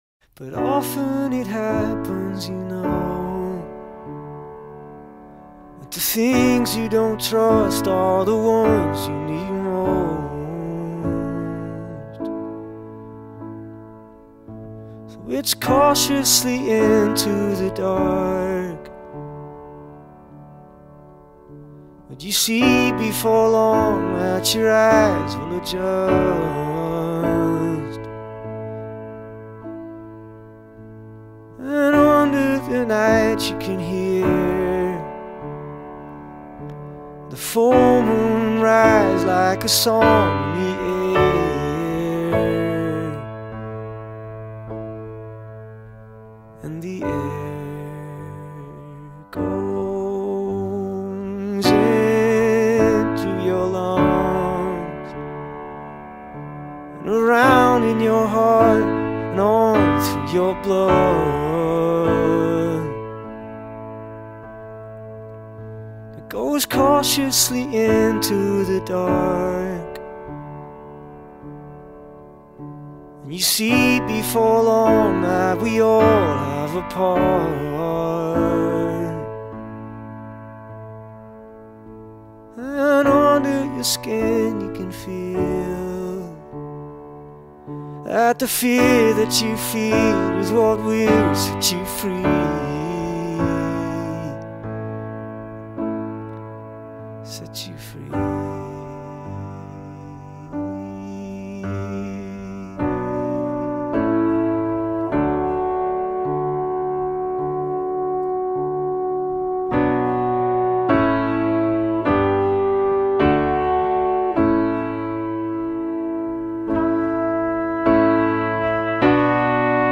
piano
Tags2000s 2008 Canada indie Rock